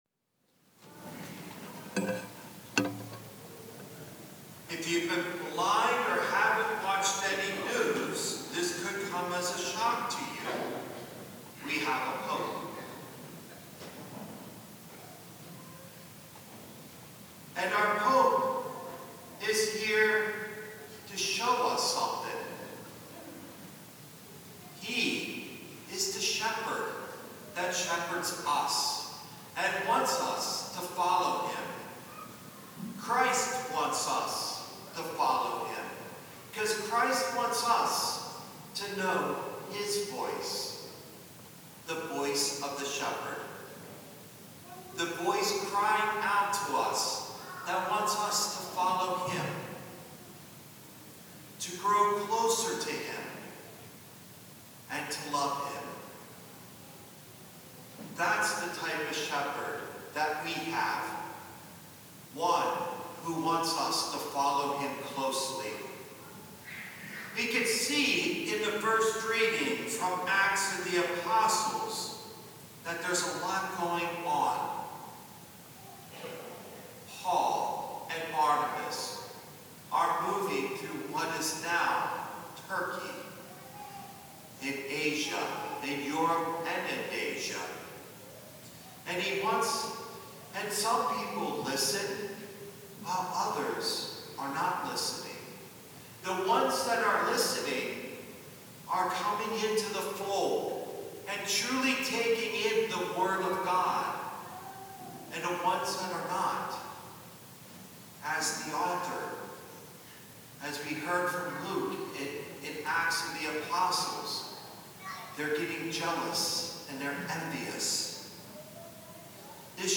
homily0511.mp3